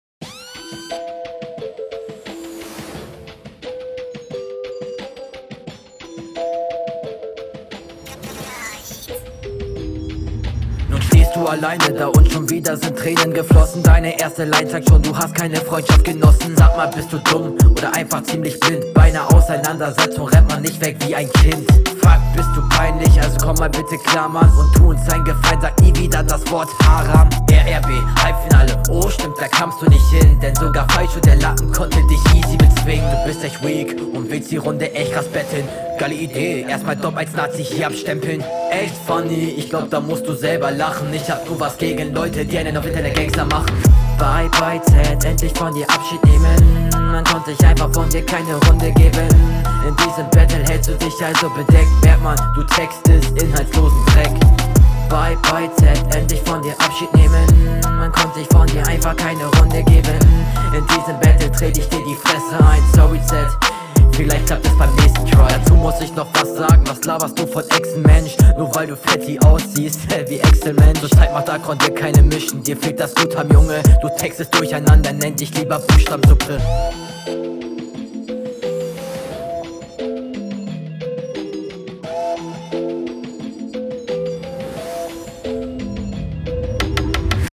Flow: Auch voll solide, hier stören mich nur wieder die kleinen Staccato Flows und die …
Gute Patterns, passender Stimmeneinsatz und gute Delivery.